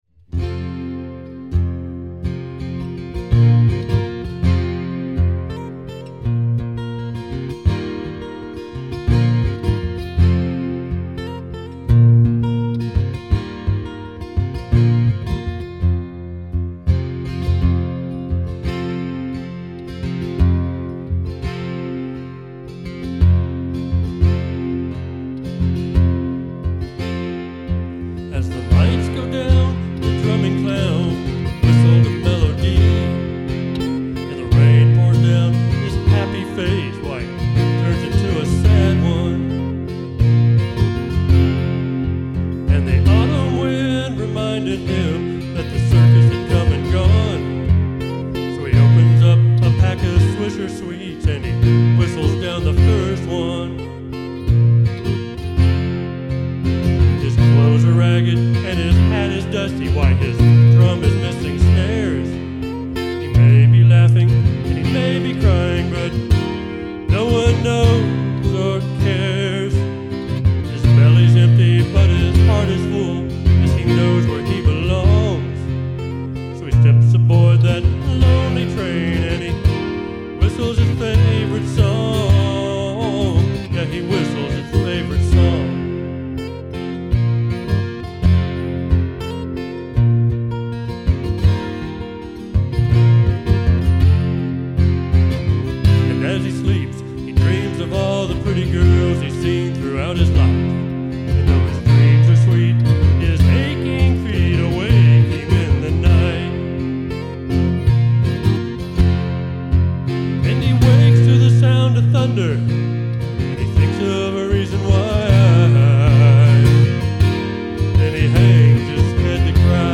Live Recordings!
I just got a new recorder to use at my live performances.